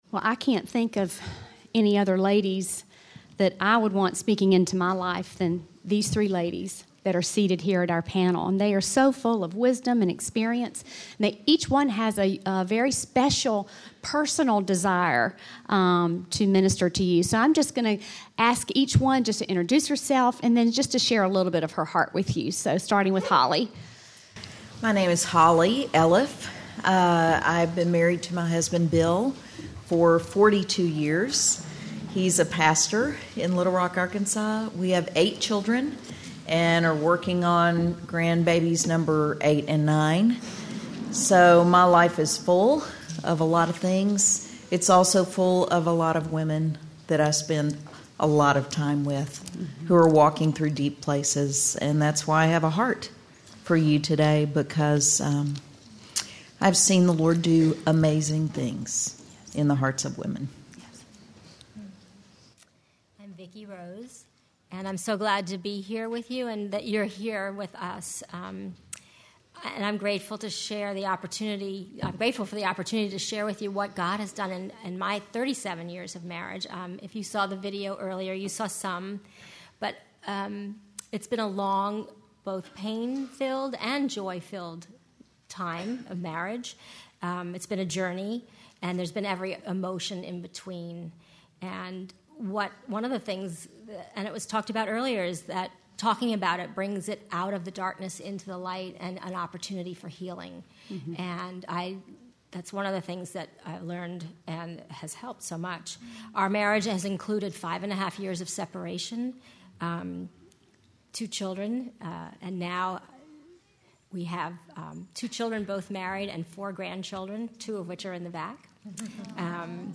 Marriage Panel: Truth and Hope for Hurting Marriages
Whether your marriage is suffering or you desire to minister to those in difficult marriages, you’ll receive encouragement and biblical counsel from four women who understand.